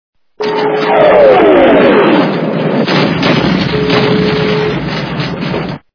При прослушивании СМС - Взрыв бомбы качество понижено и присутствуют гудки.
Звук СМС - Взрыв бомбы